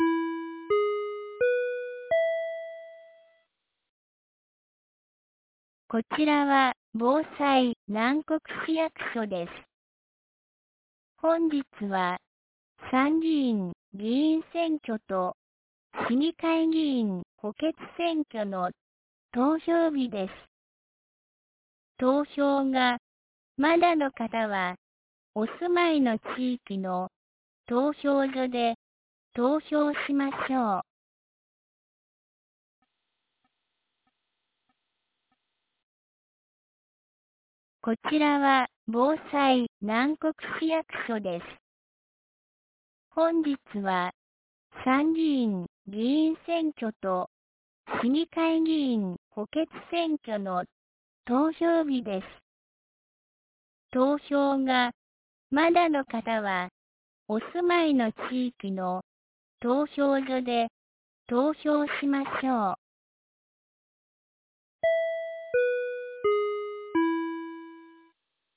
2025年07月20日 10時01分に、南国市より放送がありました。